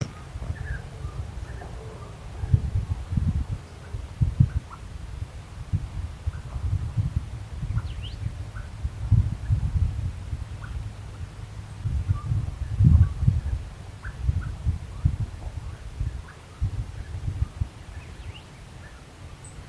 Grey-cowled Wood Rail (Aramides cajaneus)
Location or protected area: Parque Nacional El Palmar
Condition: Wild
Certainty: Observed, Recorded vocal